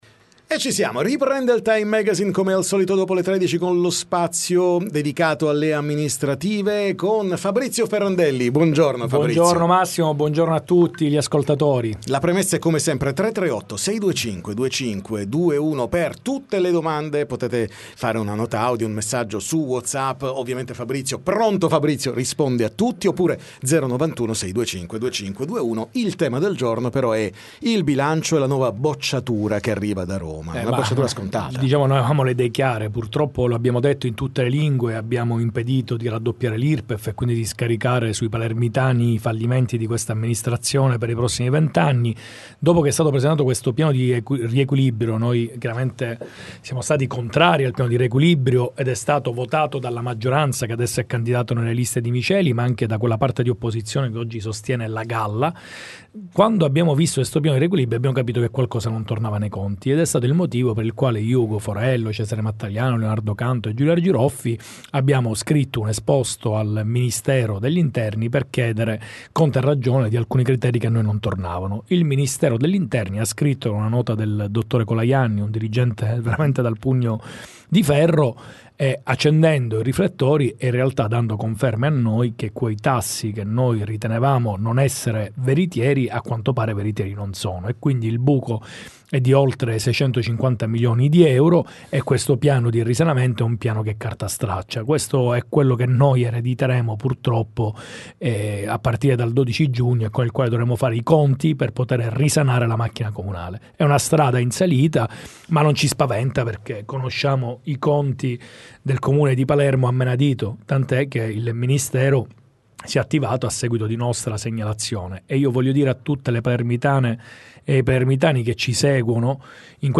TM intervista Fabrizio Ferrandelli